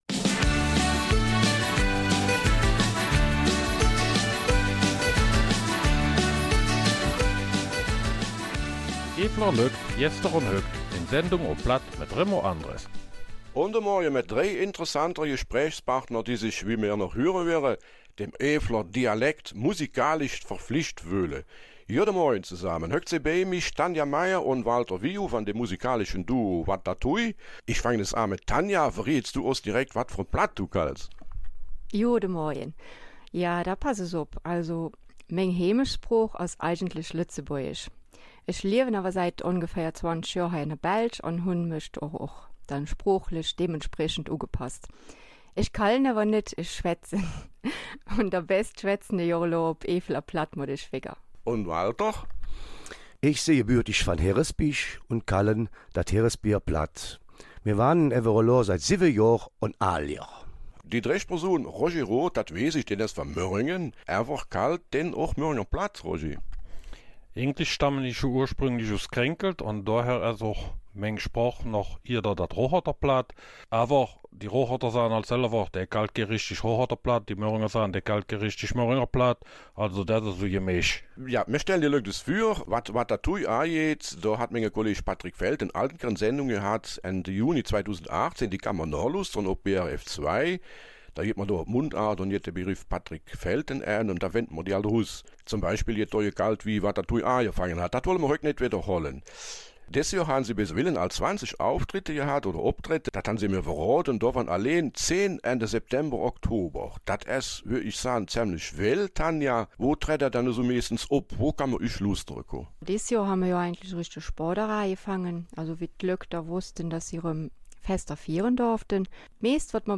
Zwei neue Lieder stellt das Duo am Sonntag, dem 20. November, auf BRF2 vor.